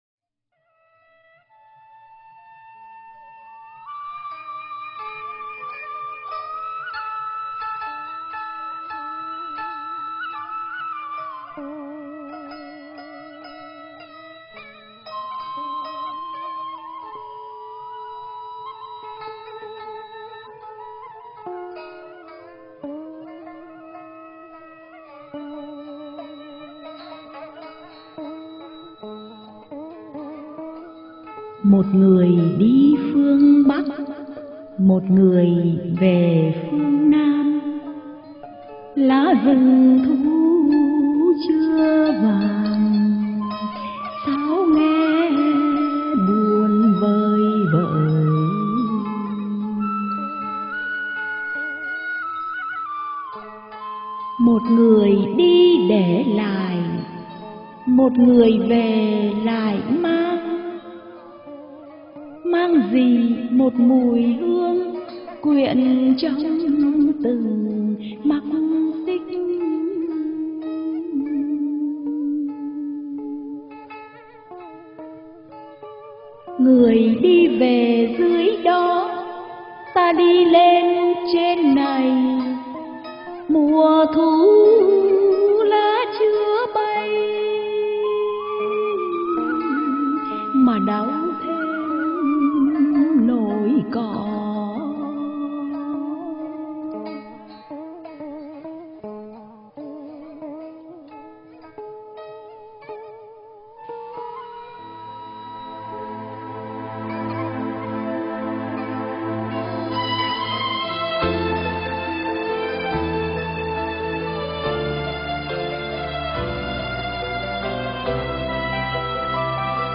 ngâm và hát